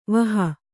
♪ vaha